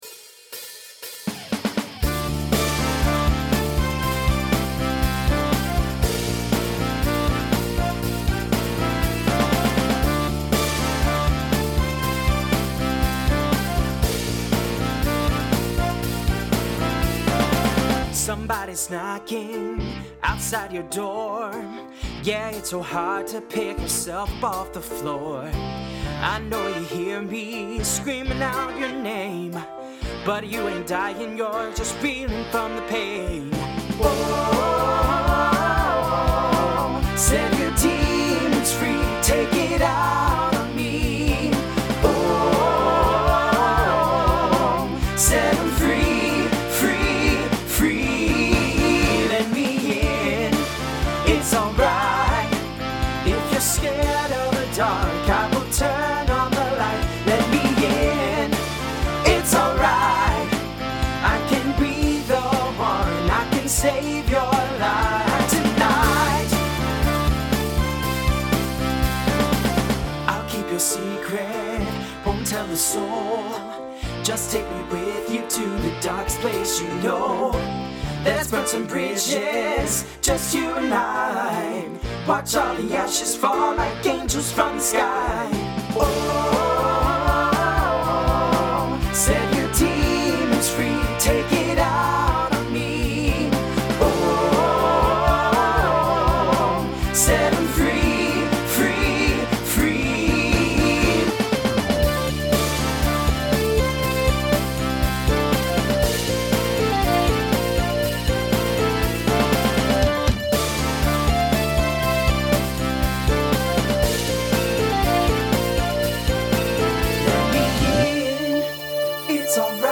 Voicing TTB Instrumental combo Genre Country